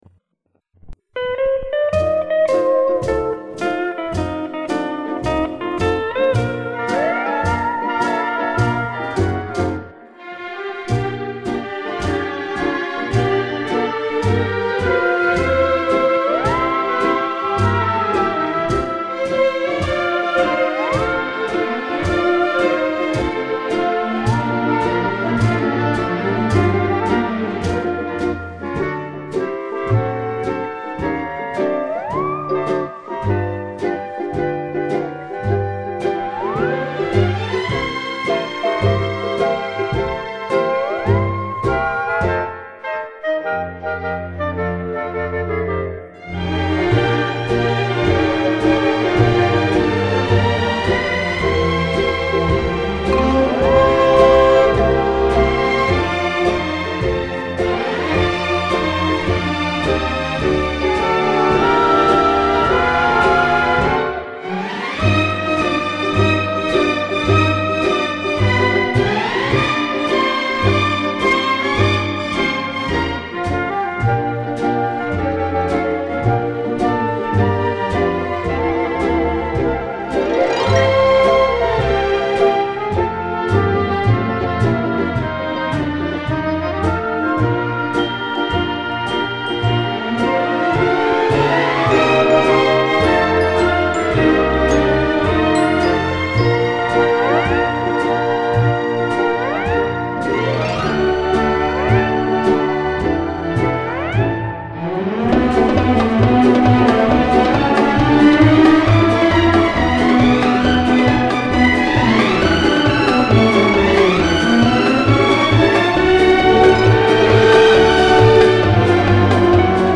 Genre:Easy  Listeing